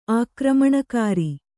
♪ ākramaṇakāri